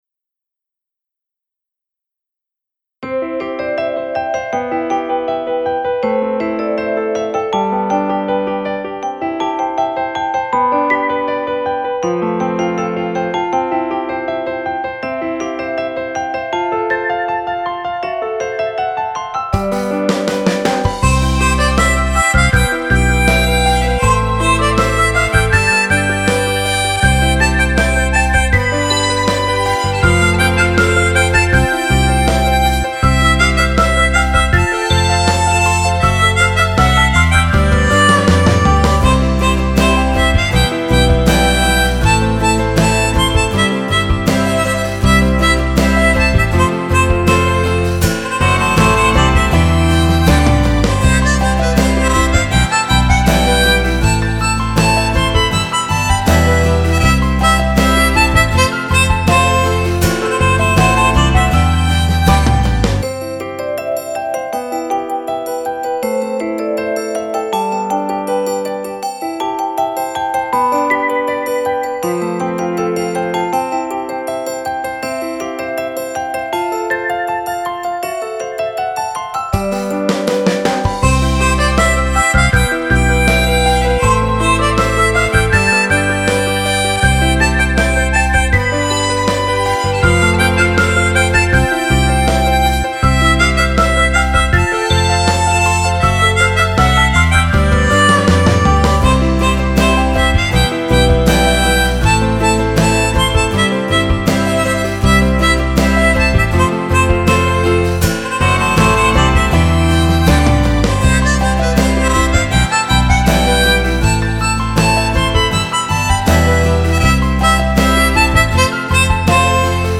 دانلود آهنگ ساز دهنی غمگین و عاشقانه